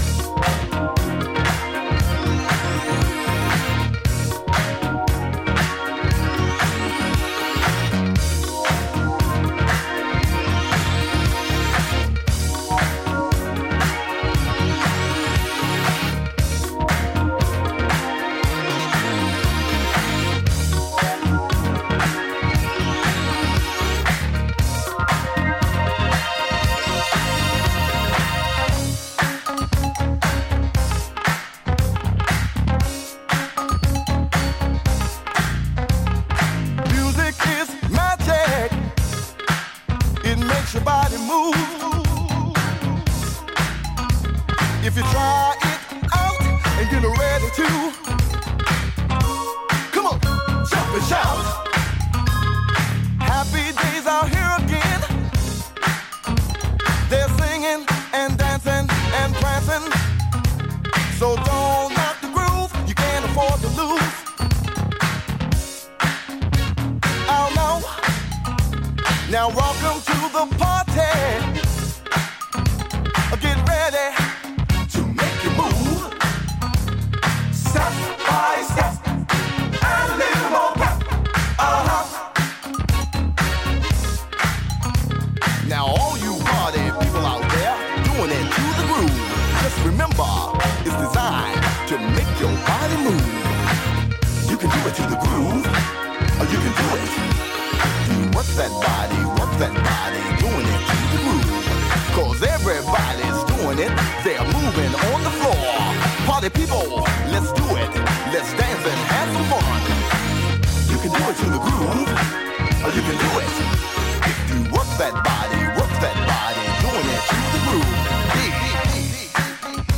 It’s a masterpiece of “funk love music”.